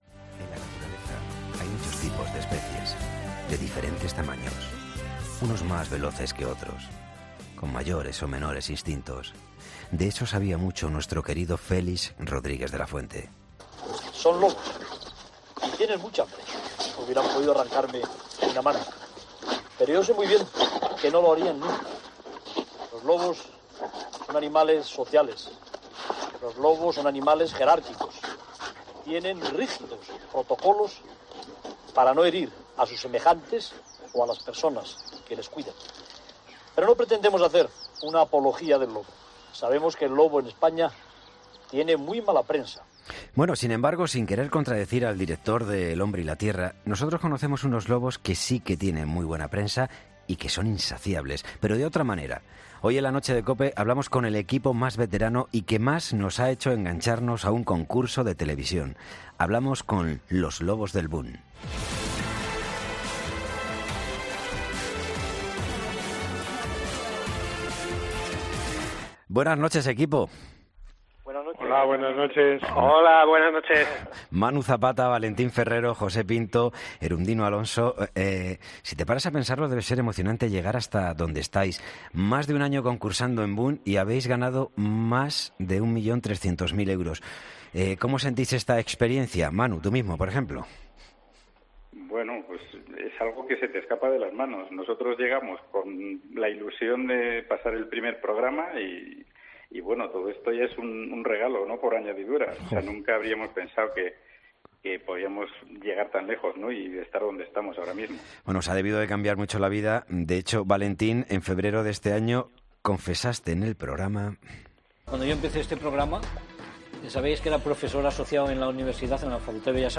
hablamos con 'Los Lobos', el equipo más veterano y que más nos ha hecho engancharnos al concurso de televisión...